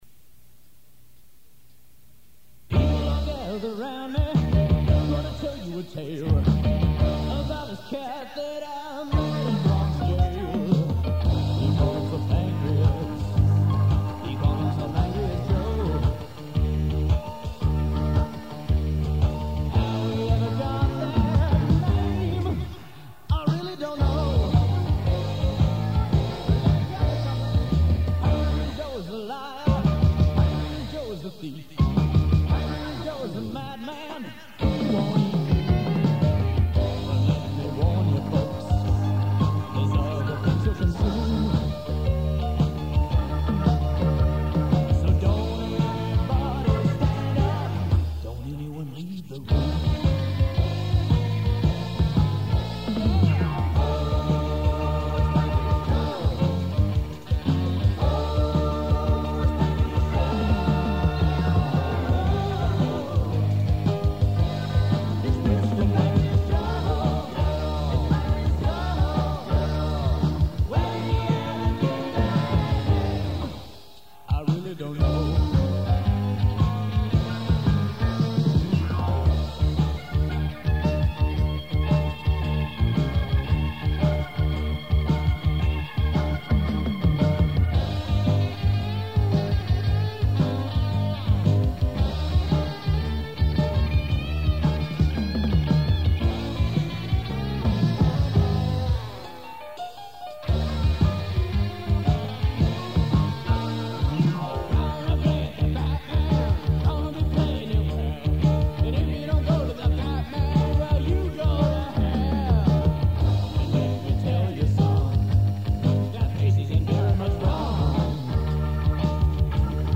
blues songs